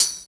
88 HAT+TMB-R.wav